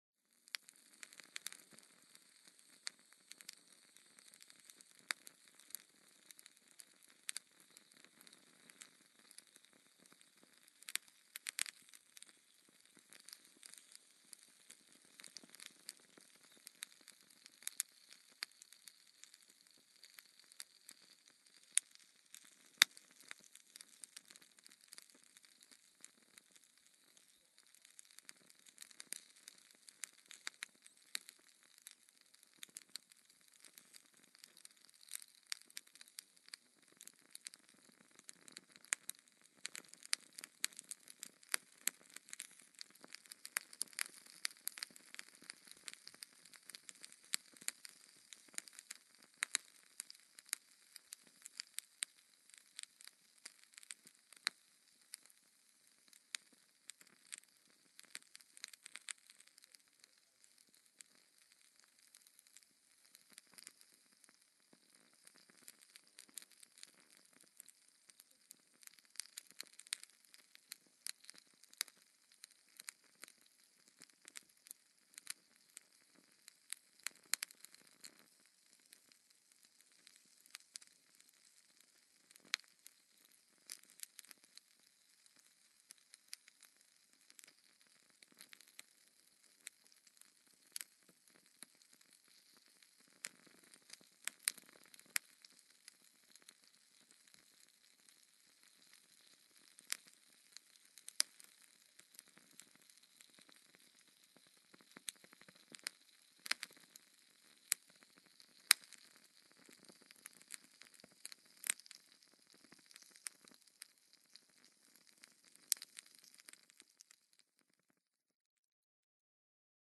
Звуки факела
Звуки пламени: звук горящего факела в руке